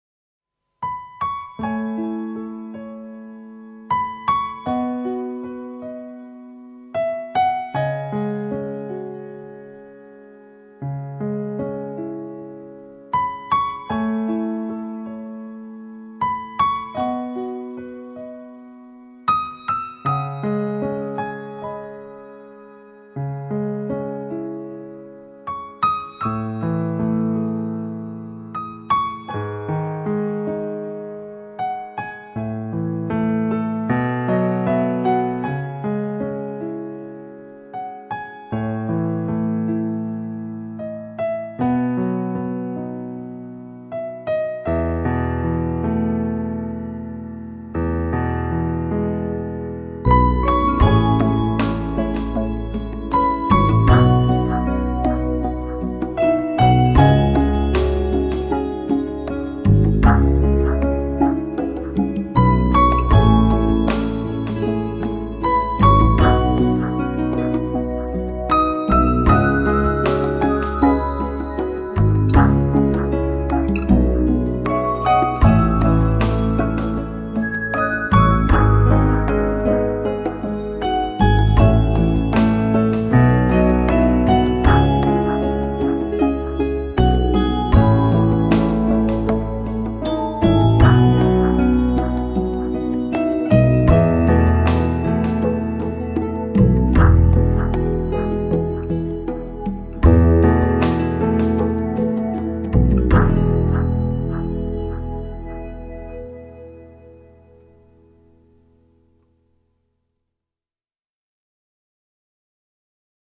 天籁钢琴, 网络美文 你是第7837个围观者 0条评论 供稿者： 标签：, ,